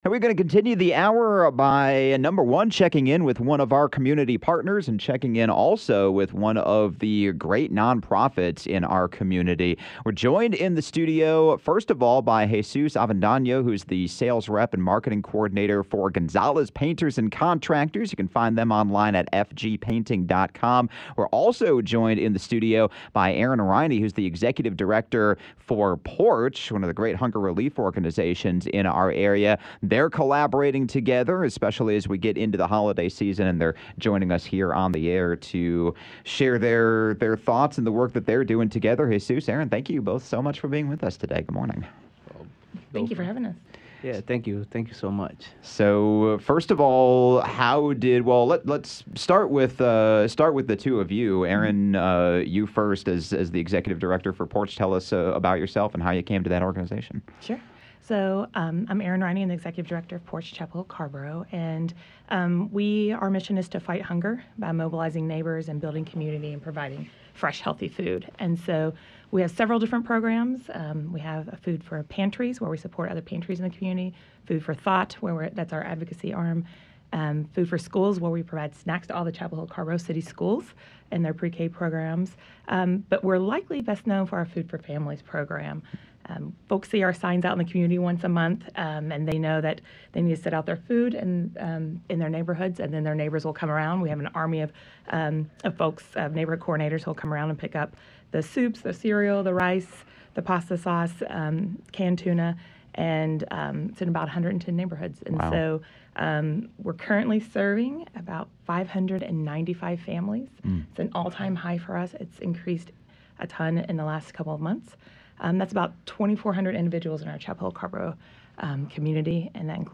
Tune in to 97.9 The Hill and check back here on Chapelboro for monthly conversations about community good, local partnerships, transformation and progress in a series featuring Gonzalez Painters and Contractors speaking with people doing some fantastic things in our community.